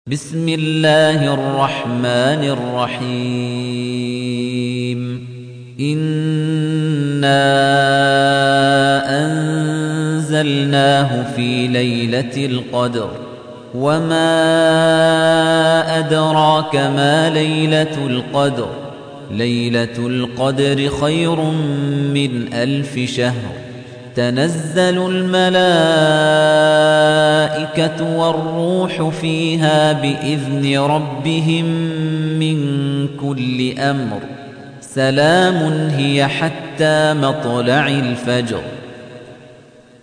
تحميل : 97. سورة القدر / القارئ خليفة الطنيجي / القرآن الكريم / موقع يا حسين